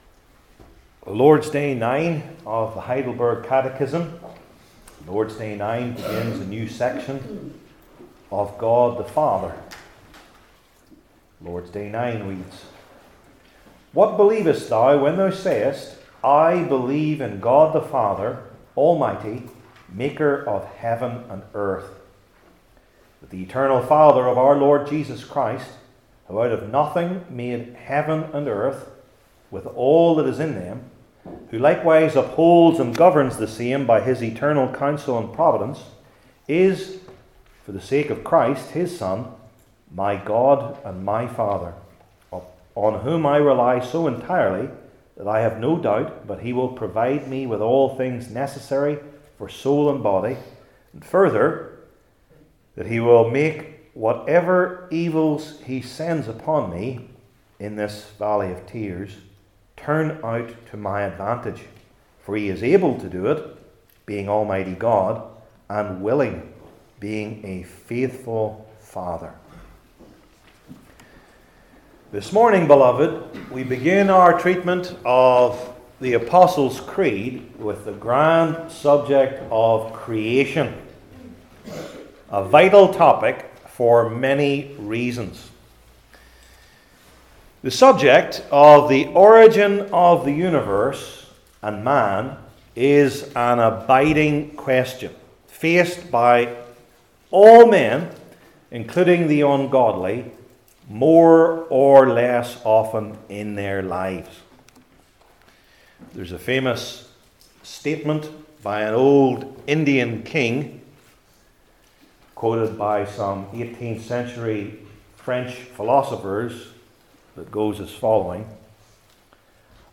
Heidelberg Catechism Sermons I. The Knowledge of Creation II.